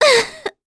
Talisha-Vox_Damage_kr_03.wav